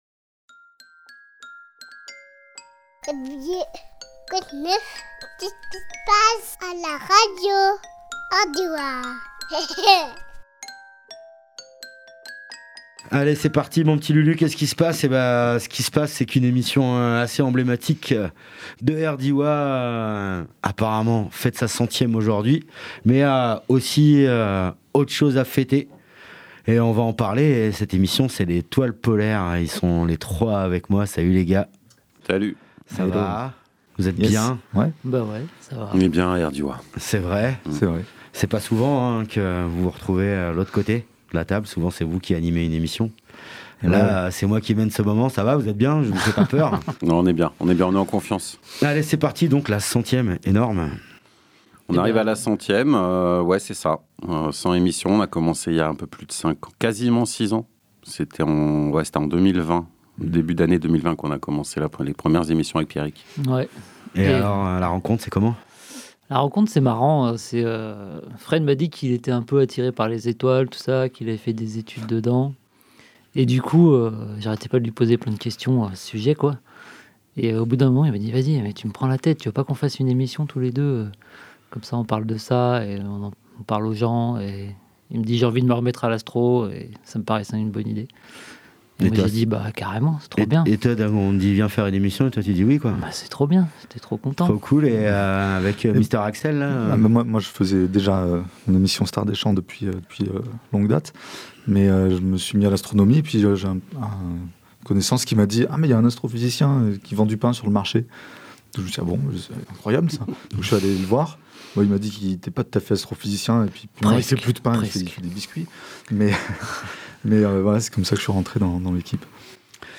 Emission - Interview L’Étoile Polaire, la centième !